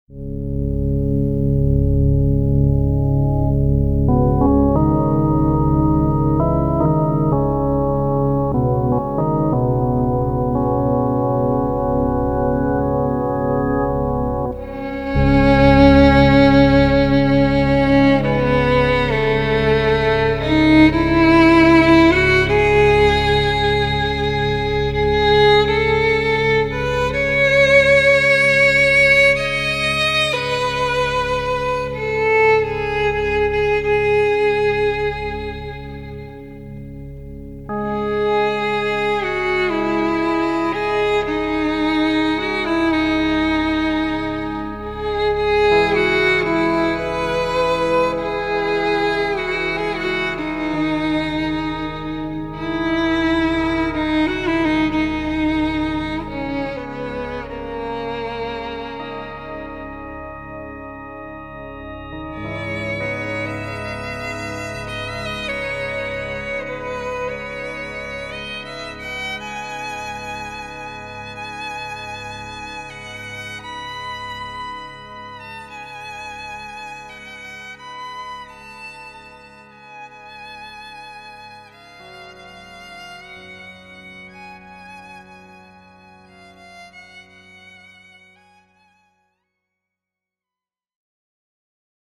sonata for violin and organ